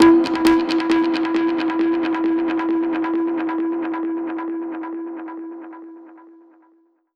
Index of /musicradar/dub-percussion-samples/134bpm
DPFX_PercHit_D_134-03.wav